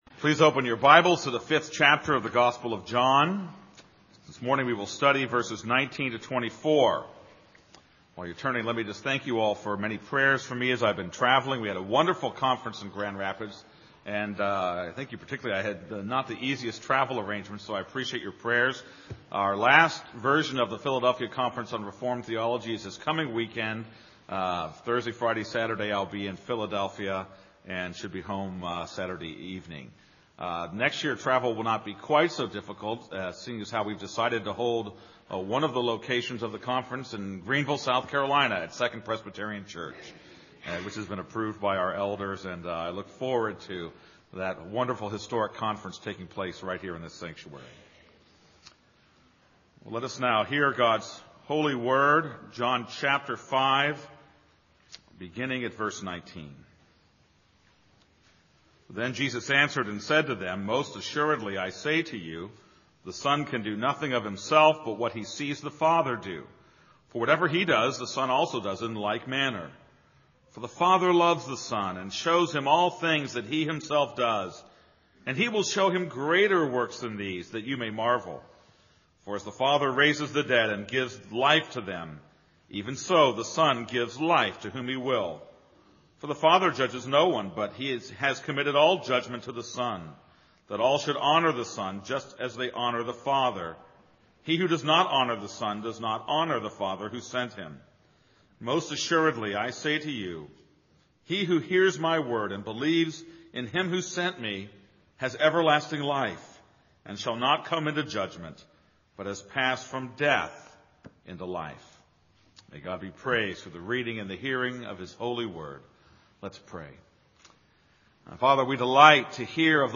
This is a sermon on John 5:19-24.